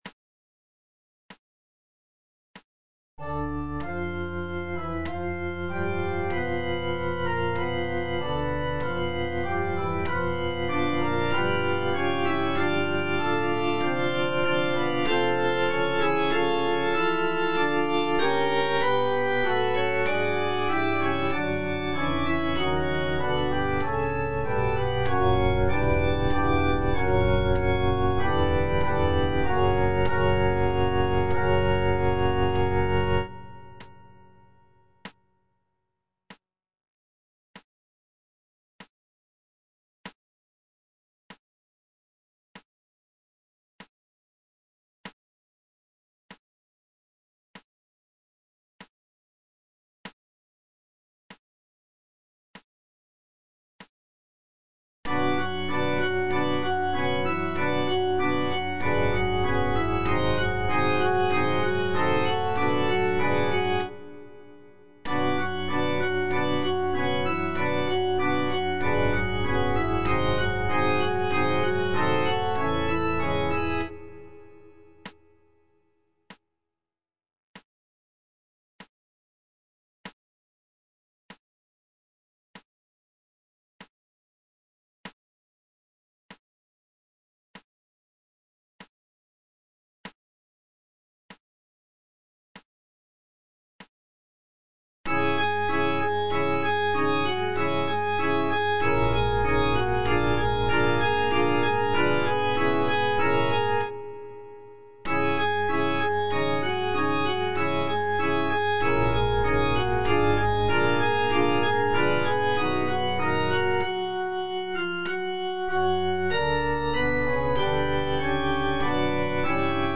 G. FAURÉ - REQUIEM OP.48 (VERSION WITH A SMALLER ORCHESTRA) Offertoire - Soprano (Voice with metronome) Ads stop:
Gabriel Fauré's Requiem op. 48 is a choral-orchestral work that is known for its serene and intimate nature.
This version features a reduced orchestra with only a few instrumental sections, giving the work a more chamber-like quality.